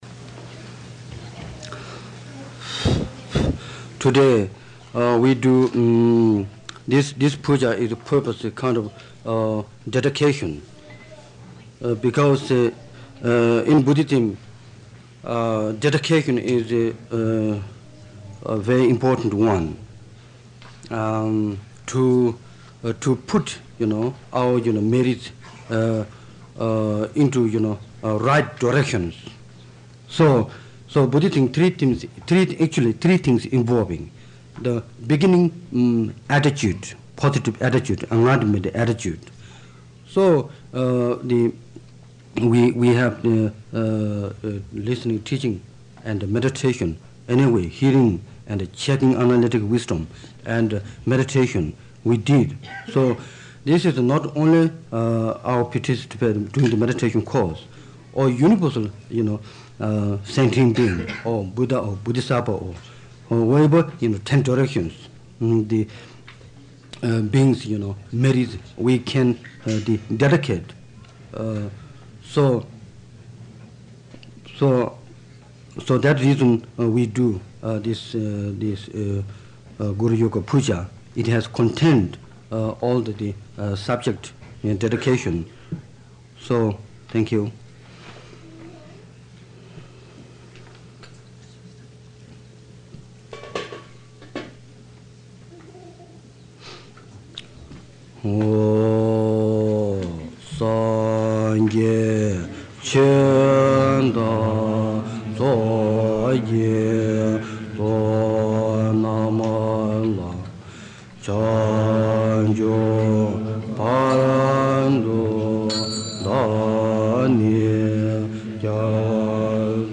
Lama Yeshe Leads Lama Chöpa : This audio file of Lama Yeshe chanting Lama Chöpa ( Guru Puja ) was recorded during a lamrim meditation course with His Holiness Zong Rinpoche held at Camp Kennolyn, a camping ground near Santa Cruz, California, in May–June 1978. The mp3 audio begins with a brief introduction by Lama Yeshe, who explains the purpose and motivation for doing this puja.